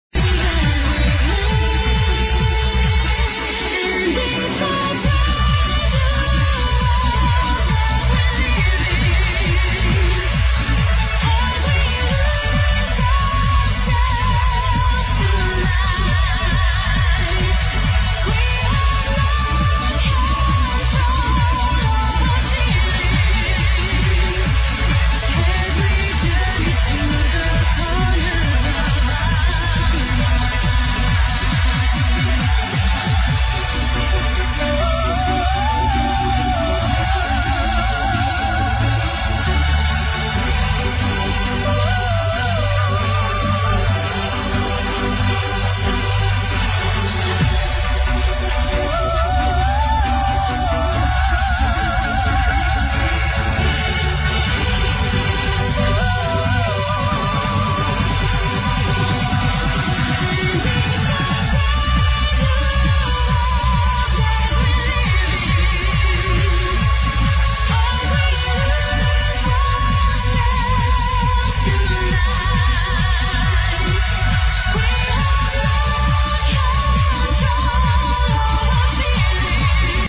This sample contain vocals that i dont saw on